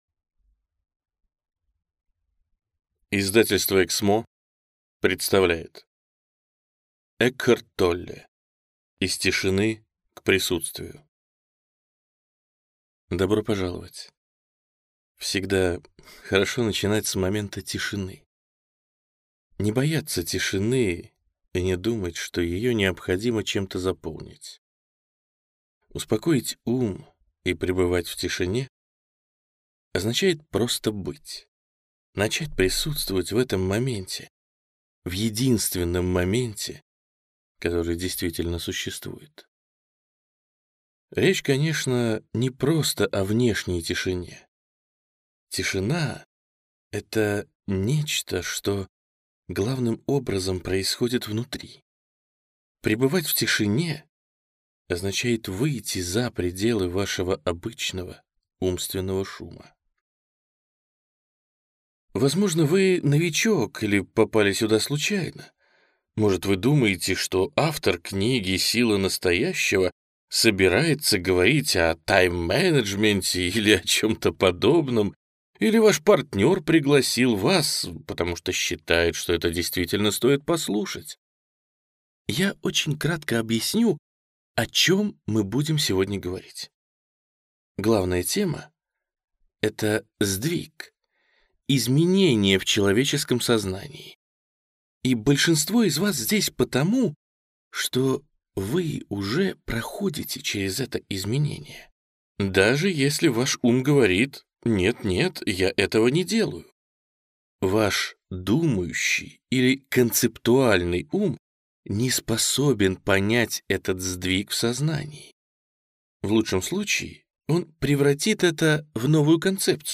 Аудиокнига От тишины к присутствию | Библиотека аудиокниг